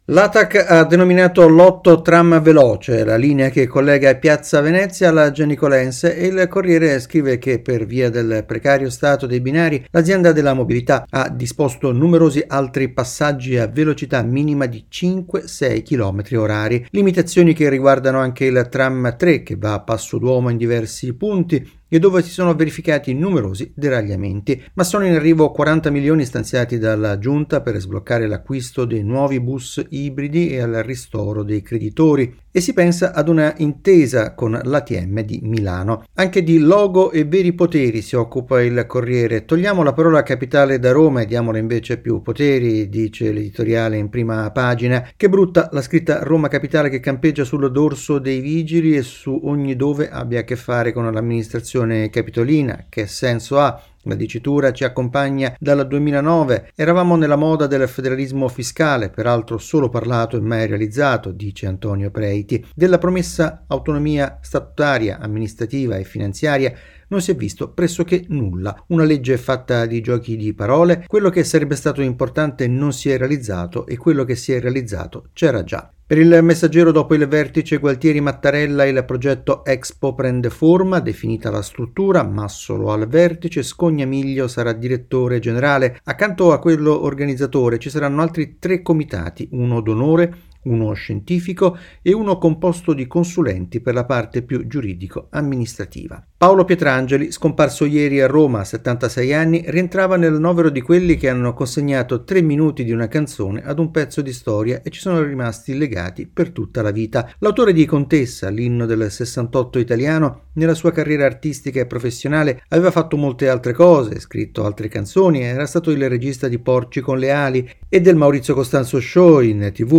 Ecco le principali notizie della giornata dalla rassegna stampa